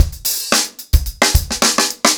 TupidCow-110BPM.13.wav